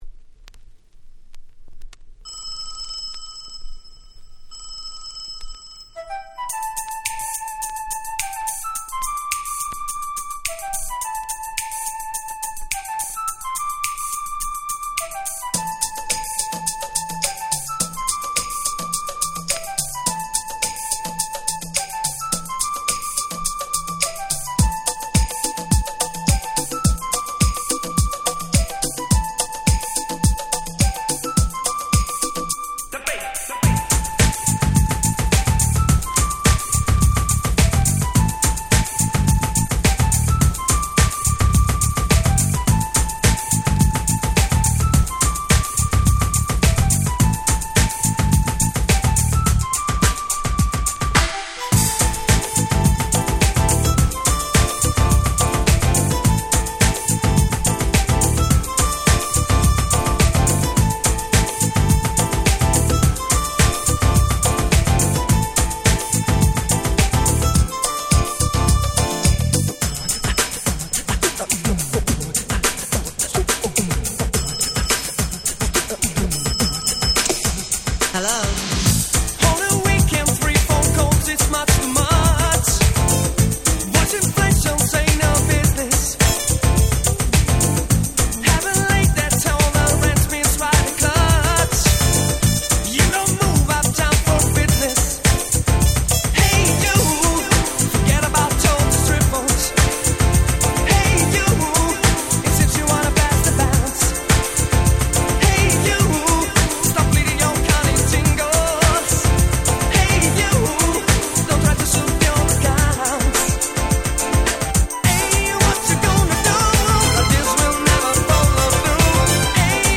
91' Very Nice Ground Beat !!
グラビ グランド イタロハウス Grand 90's R&B